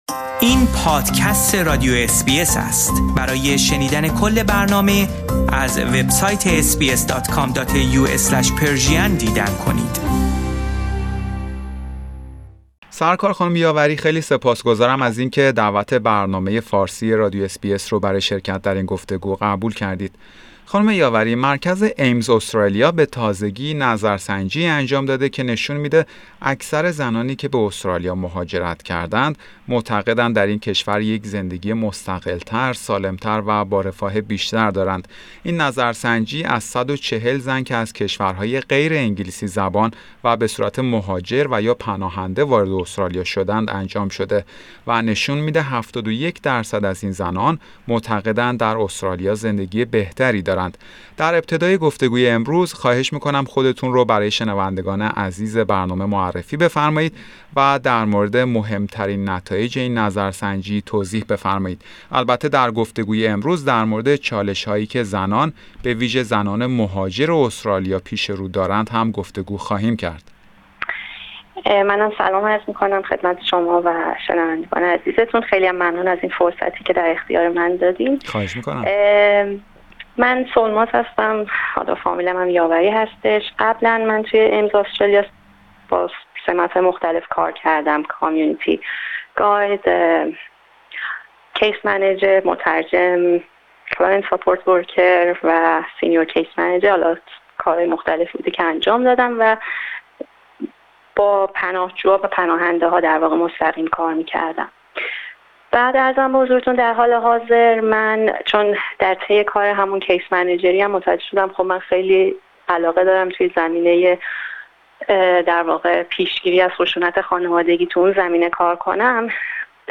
در این گفتگو همچنین به بررسی یک نظرسنجی جدید توسط مرکز AMES Australia پرداخته شده است که نشان می دهد اکثر زنانی که به استرالیا مهاجرت کرده اند معتقدند در استرالیا زندگی بهتری دارند. توجه شما به شنیدن این گفتگو جلب می شود.